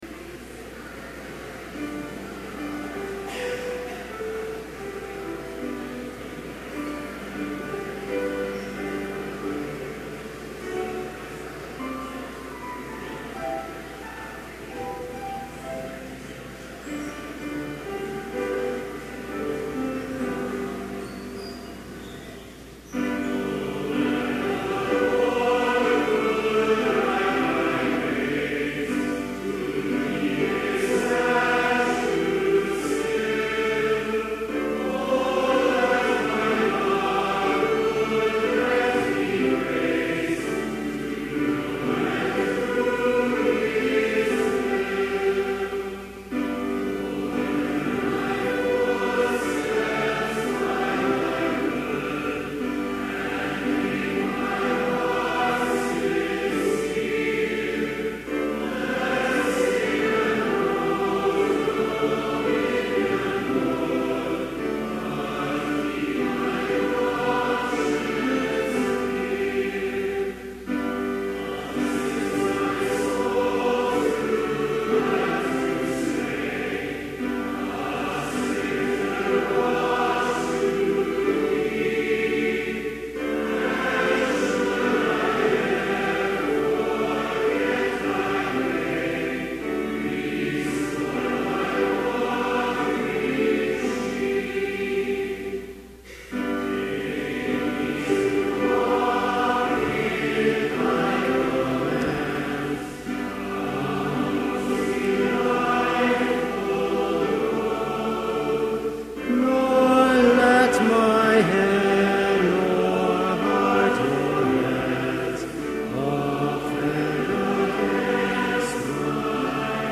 Complete service audio for Chapel - September 2, 2011